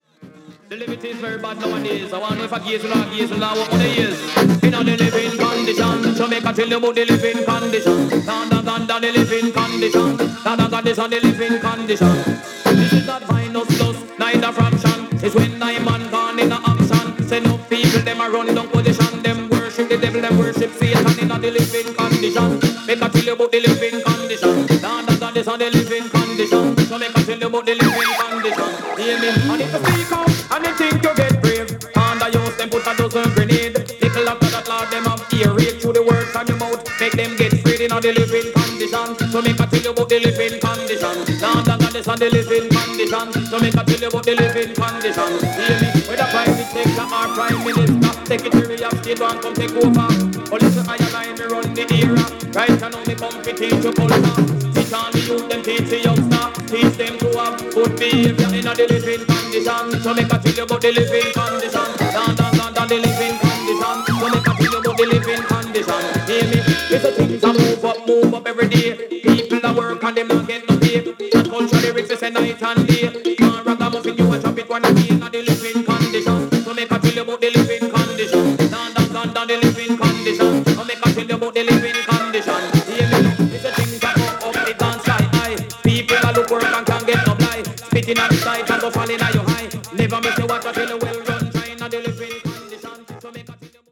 トリッキーなリズムにハマります（笑）